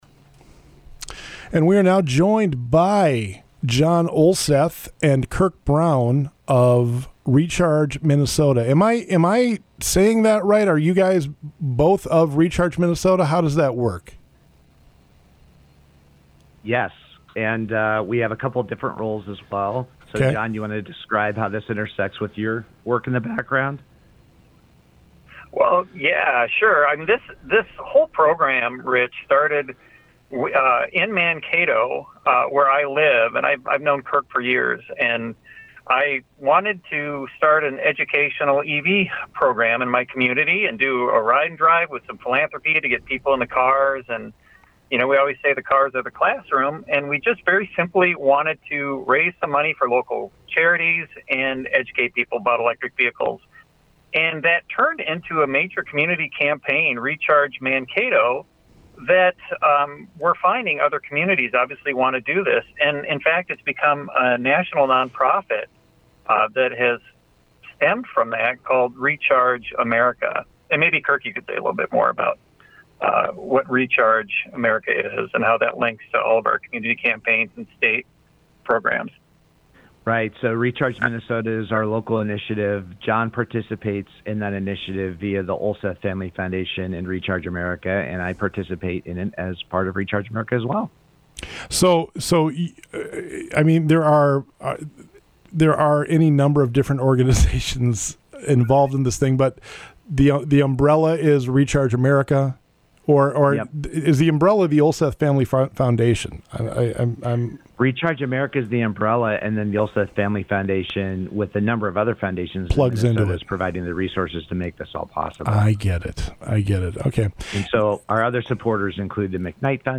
Recharge-Interview.mp3